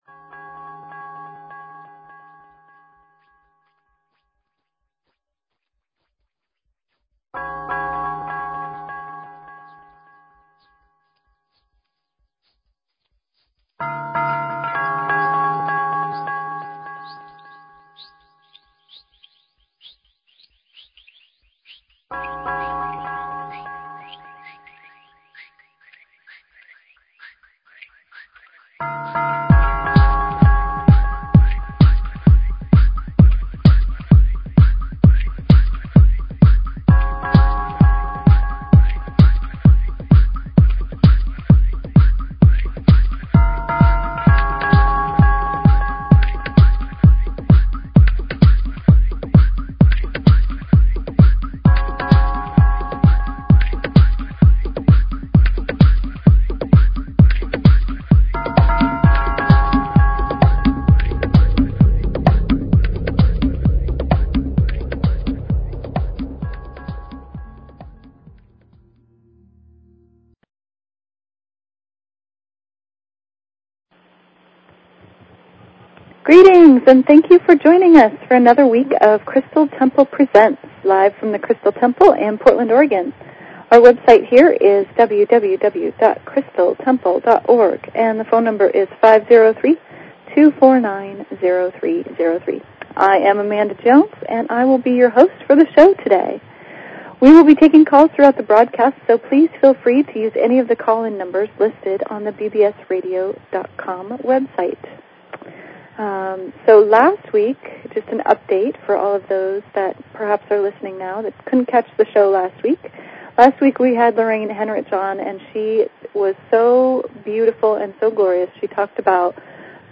Talk Show Episode, Audio Podcast, Crystal_Temple_Presents and Courtesy of BBS Radio on , show guests , about , categorized as